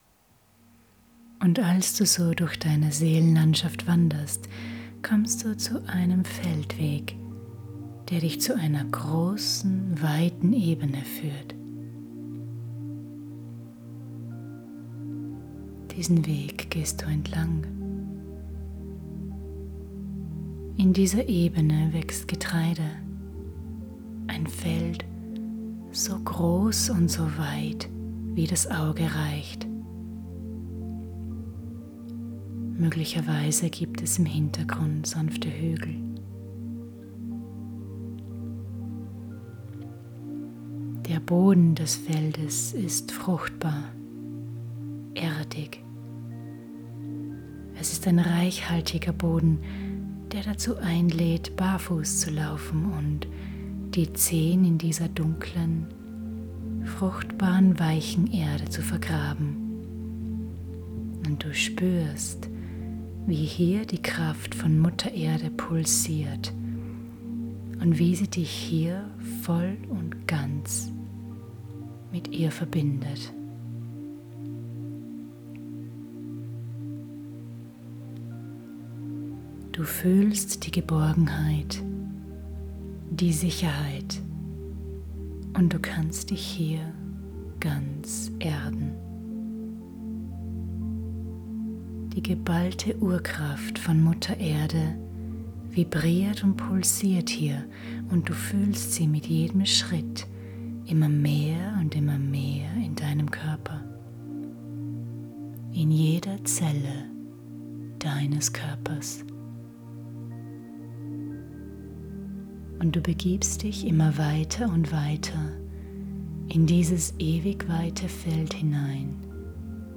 Das Seelenfeuer ist eine sehr intensive und mächtige hypnotische Trance.